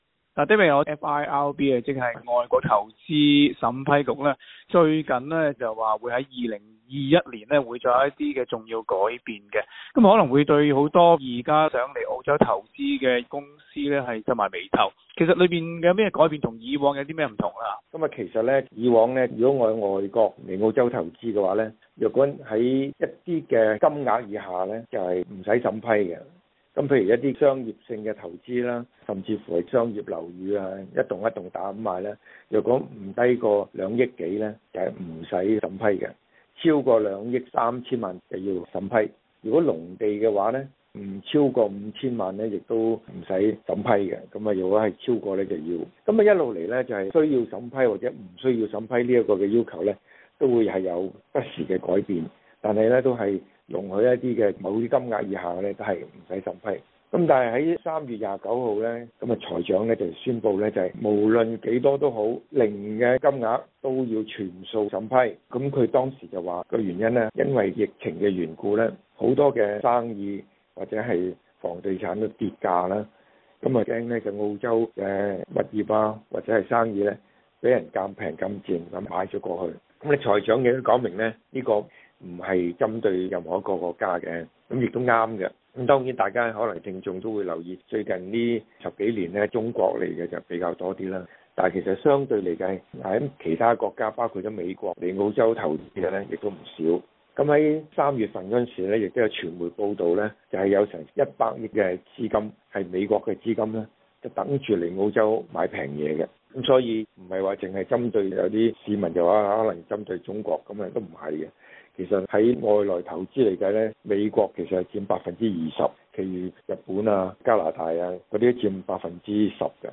详情请收听今期的访问。